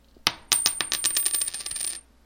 coin.mp3